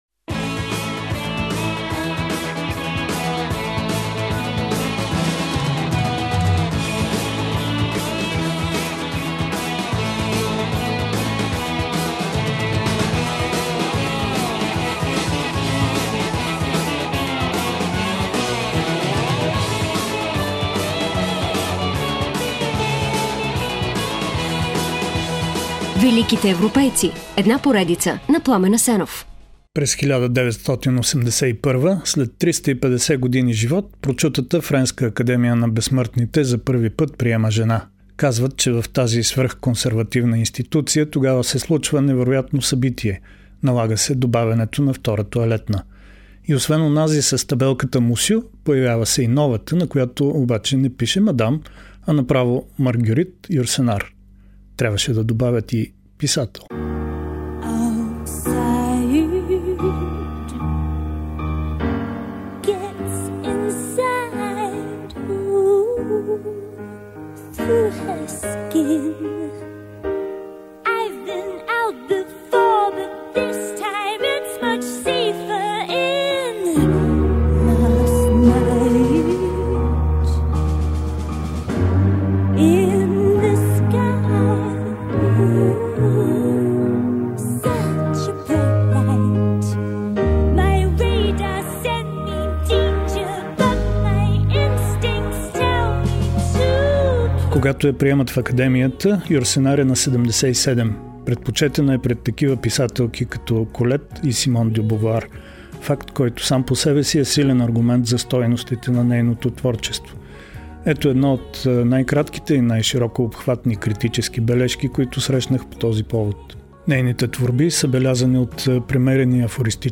В броя, посветен на френскоезичната писателка, ще чуете и малко от нейния глас…